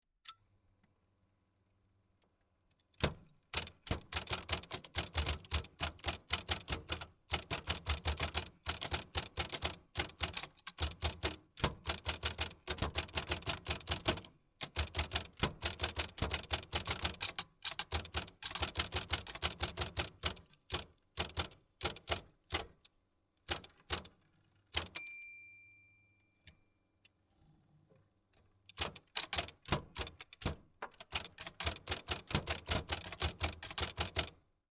Adler Gabriele 2000 typewriter